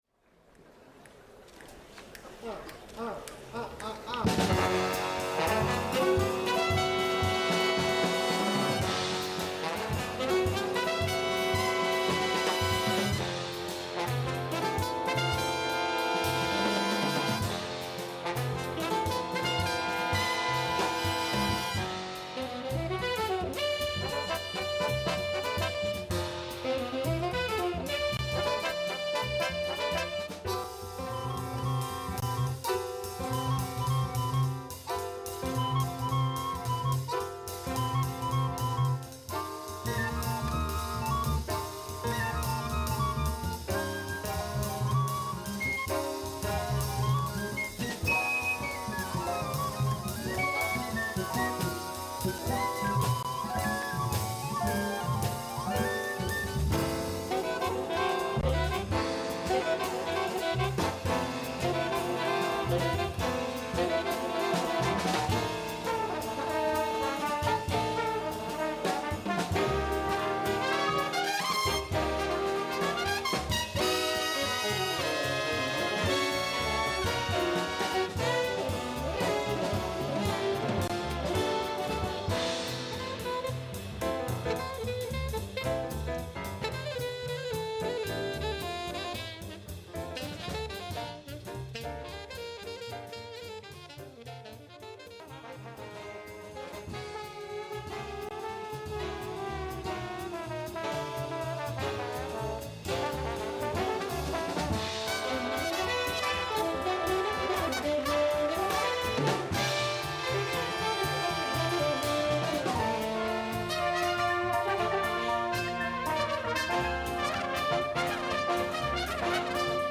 Store/Music/Big Band Charts/ARRANGEMENTS
This chart has optional bass trombone part.
Solos: alto 1, trumpet 4